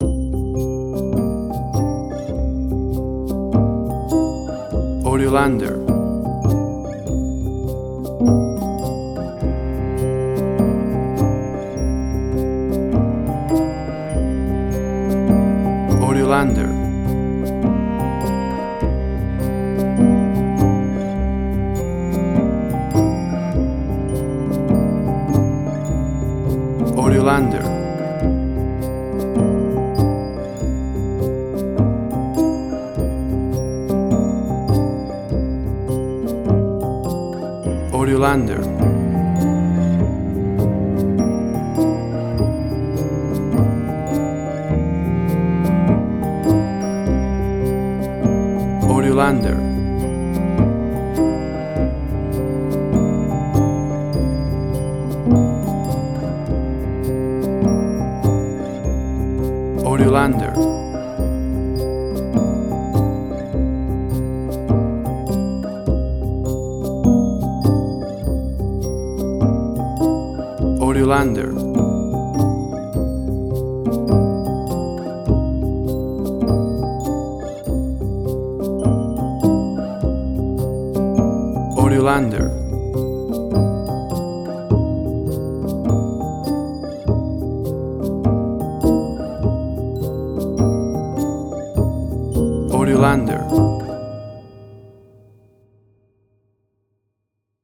WAV Sample Rate: 24-Bit stereo, 48.0 kHz
Tempo (BPM): 102